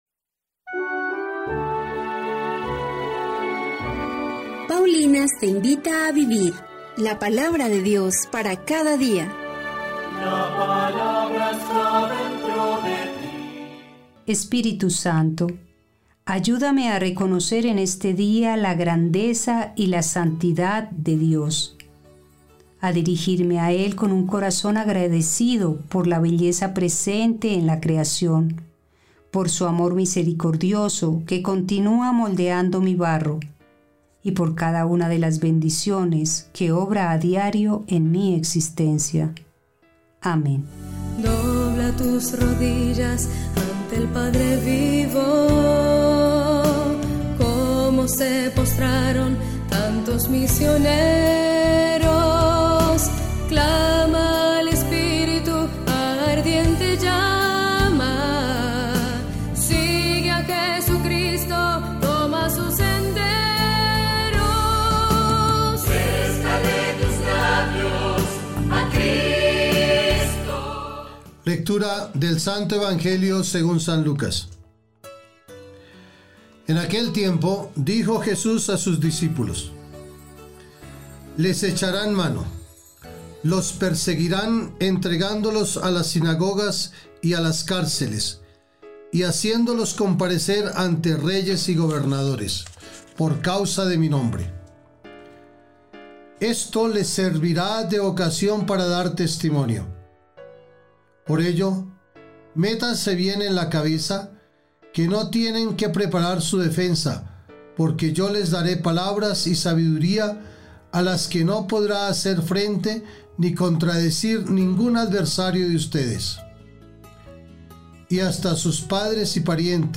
Primera Lectura